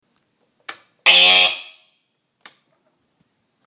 The amp was quiet while the voltage came up but then made a horrible sound like an eagle screeching at about 120dB. It was a loud, half second burst, then pulsed getting faster every time while I scrambled for the plug.
It made the sound for just a second and then blew the fuse.